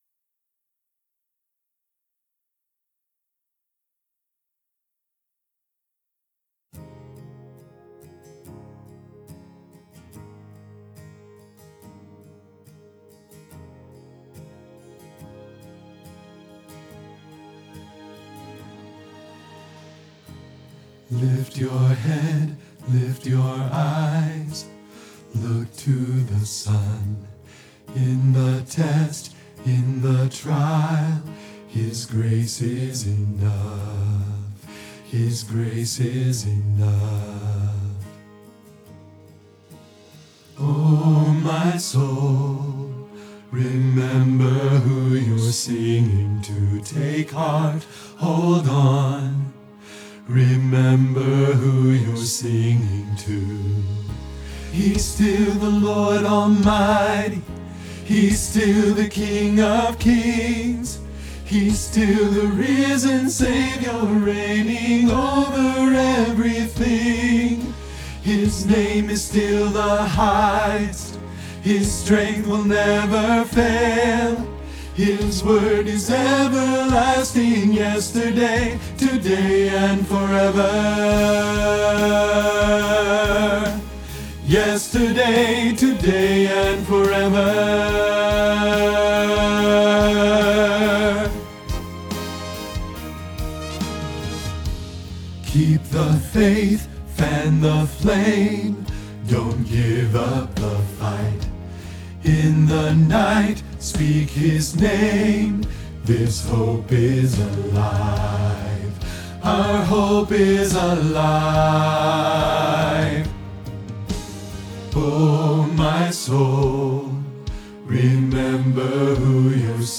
Yesterday, Today, Forever – Bass – Hilltop Choir
Yesterday, Today, Forever – Bass Hilltop Choir